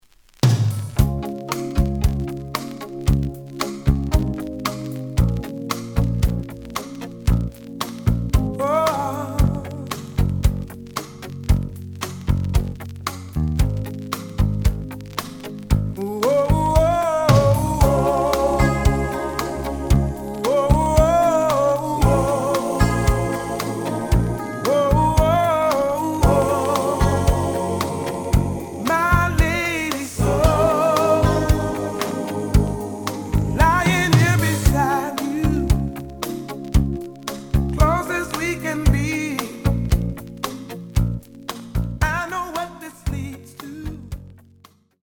The audio sample is recorded from the actual item.
●Genre: Soul, 80's / 90's Soul
Slight noise on both sides.